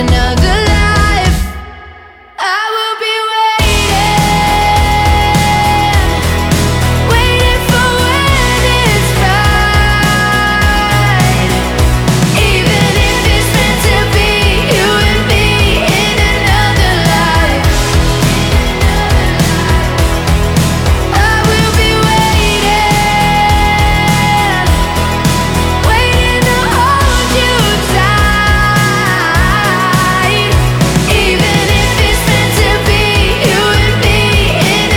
Жанр: Поп музыка Длительность